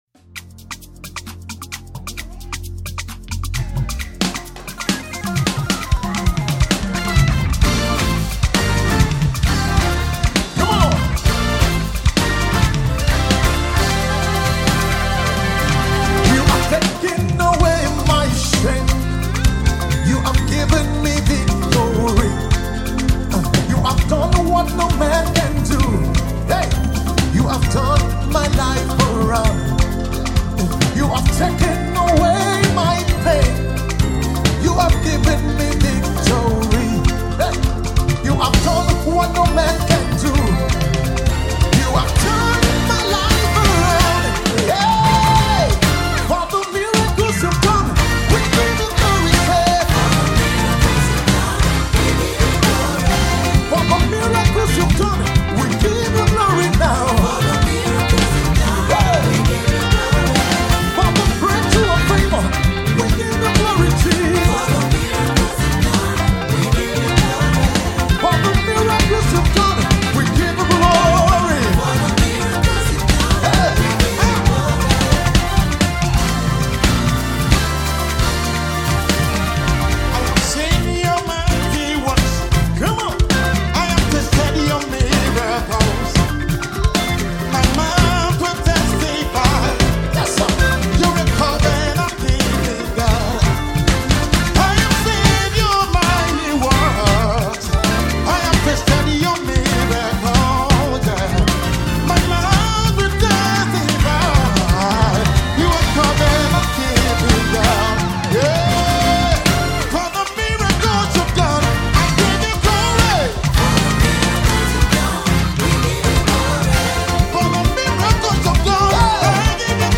Gospel MusicVideo
Recorded live at the Judah Experience Lagos Edition 2024
vibrant and energetic song